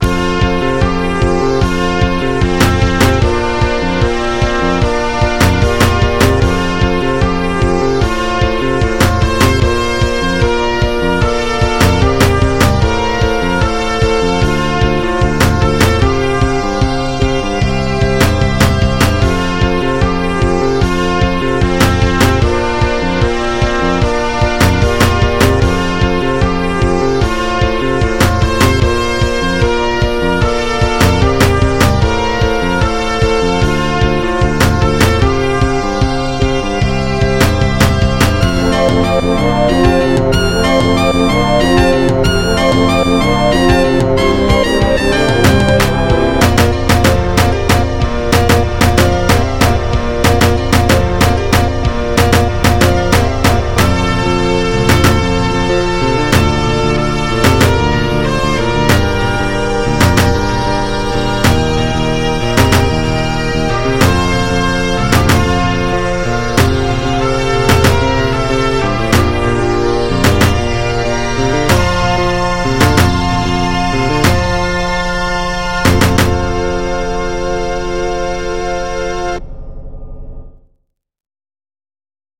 MIDI 23.11 KB MP3 (Converted) 1.26 MB MIDI-XML Sheet Music